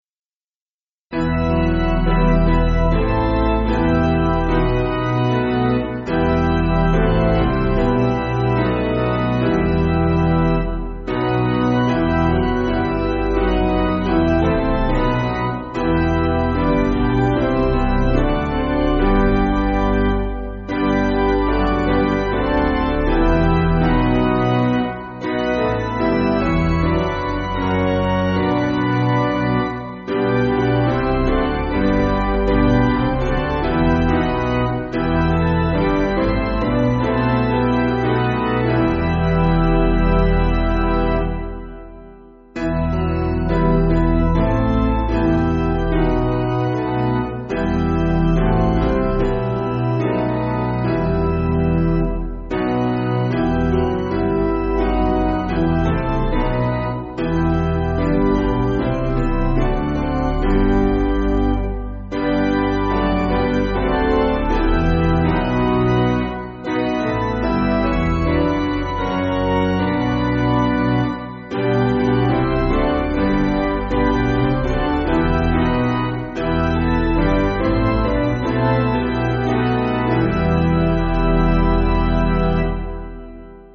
Basic Piano & Organ
(CM)   4/Em